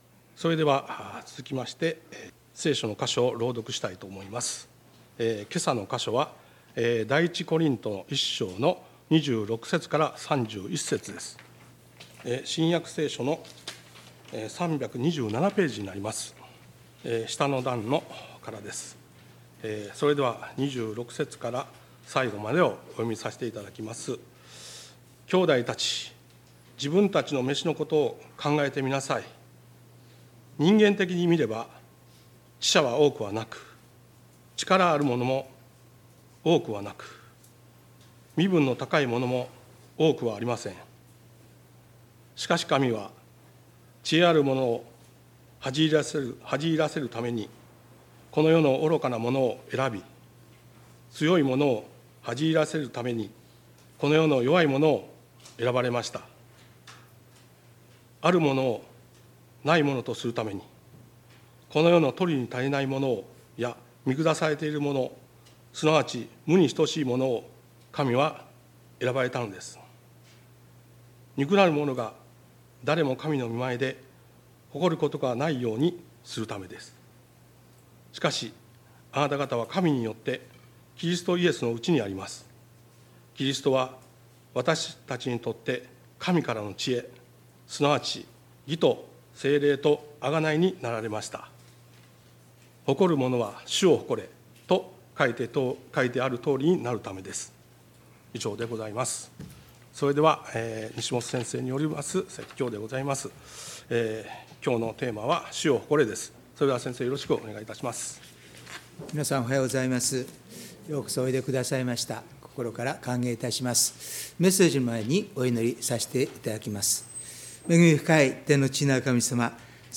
礼拝メッセージ「主を誇れ」│日本イエス・キリスト教団 柏 原 教 会